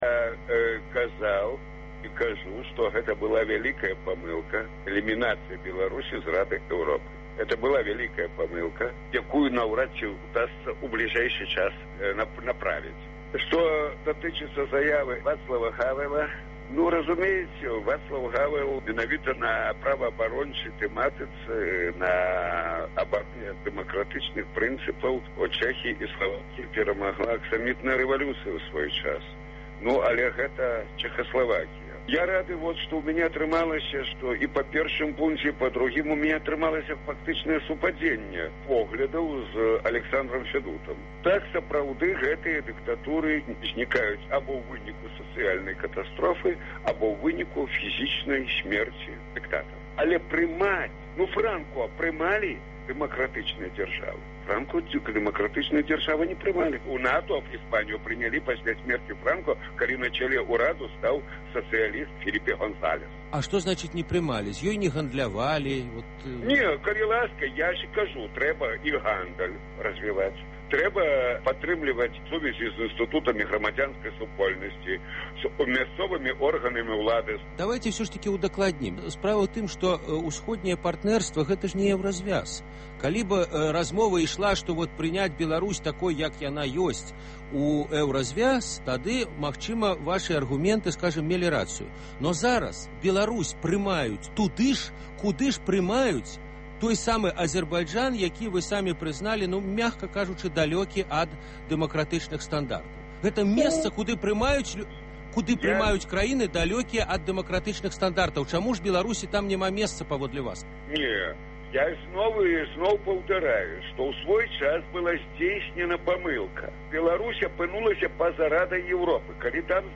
Агляд тэлефанаваньняў слухачоў